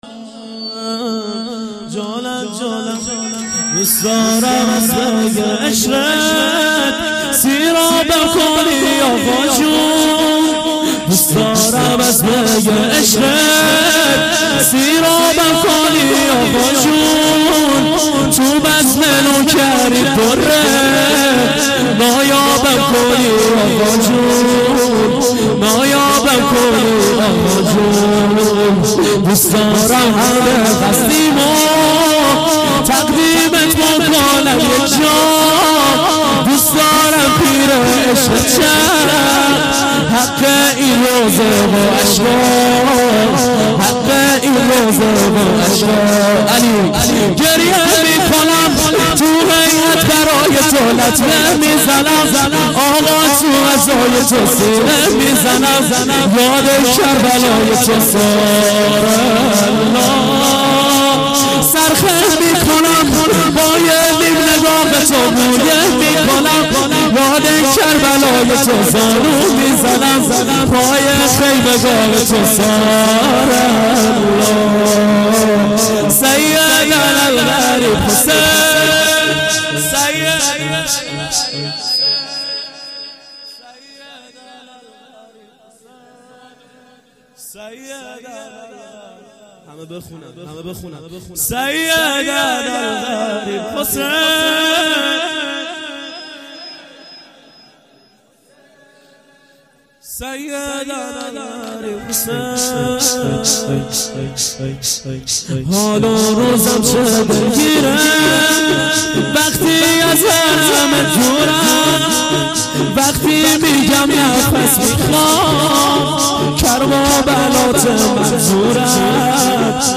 شور - دوست دارم از می عشقت